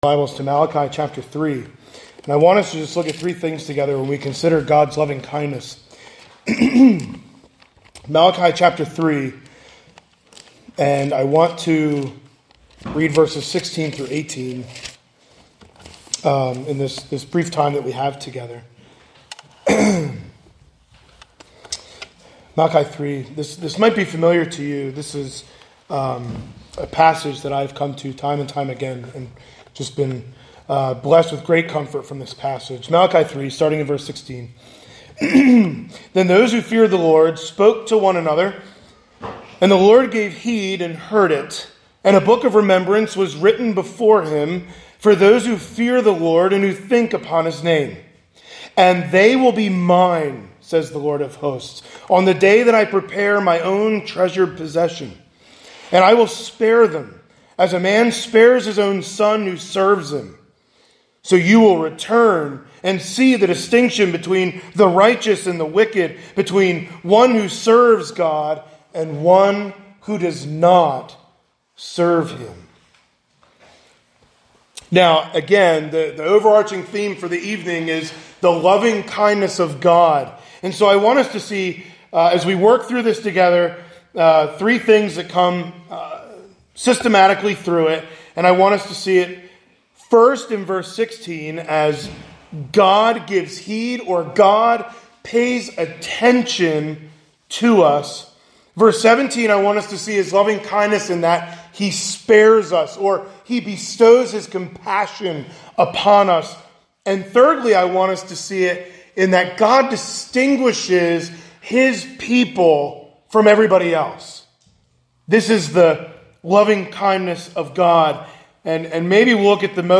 A Thanksgiving Eve Sermon